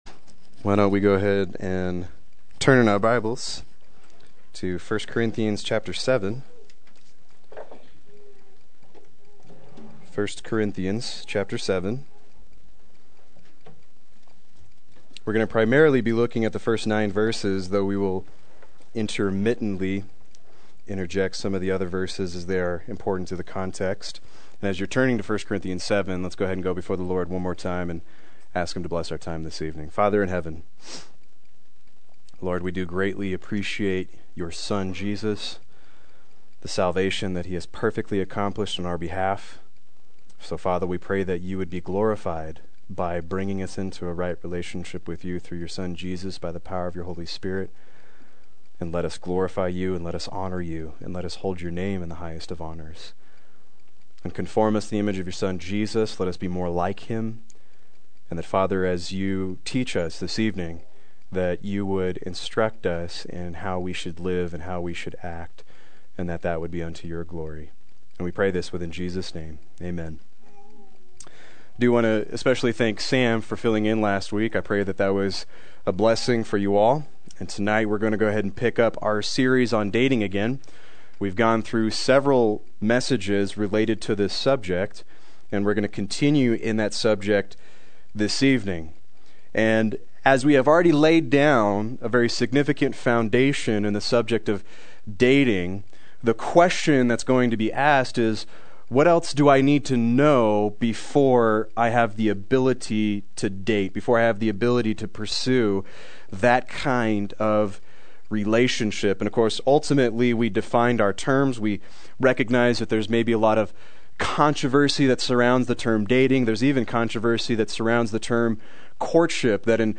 Proclaim Youth Ministry - 11/22/14
Play Sermon Get HCF Teaching Automatically.